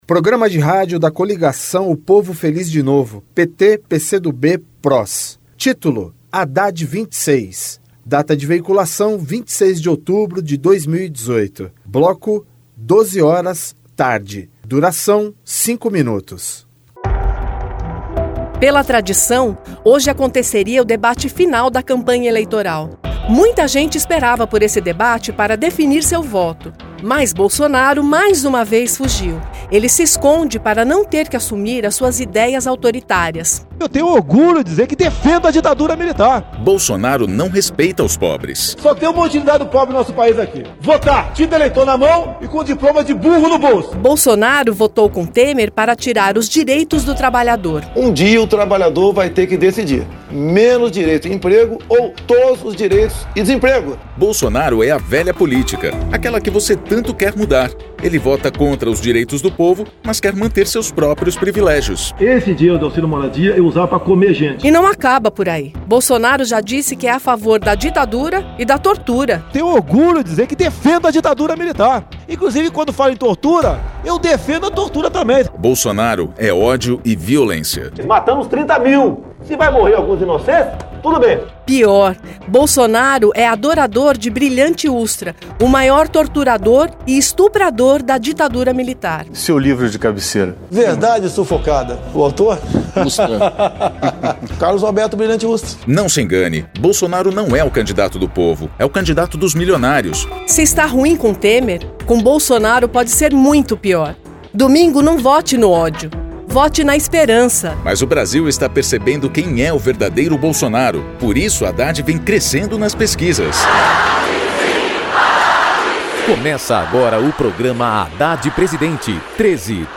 Gênero documentaldocumento sonoro
Descrição Programa de rádio da campanha de 2018 (edição 56), 2º Turno, 26/10/2018, bloco 12hrs.